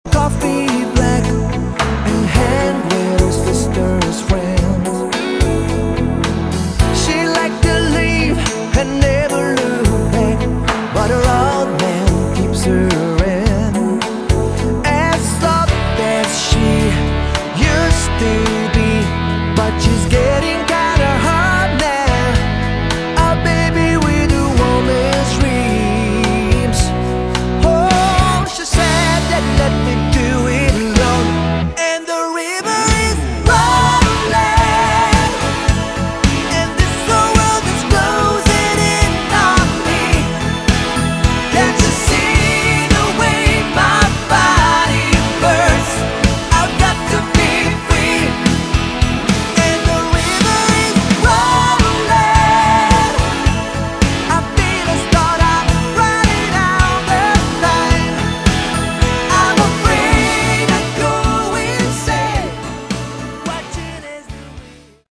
Category: AOR / Melodic Rock
vocals, guitar
bass
keyboards
drums